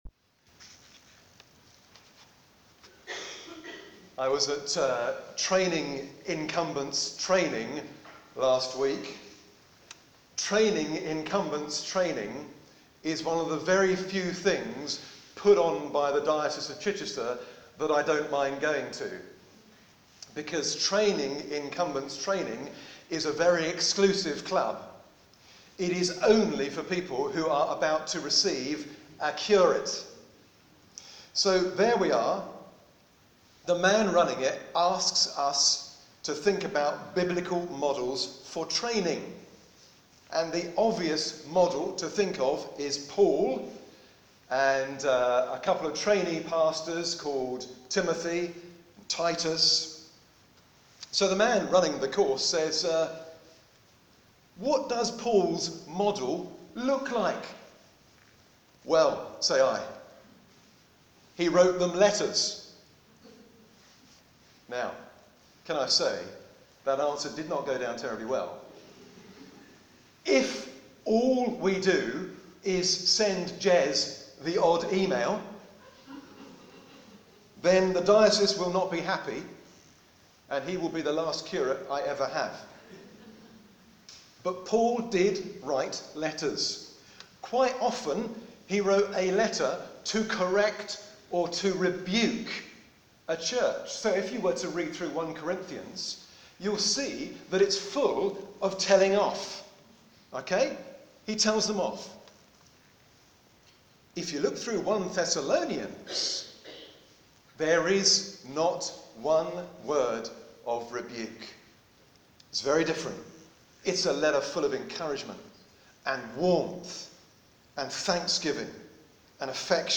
Sermons | Denton Church | Page 22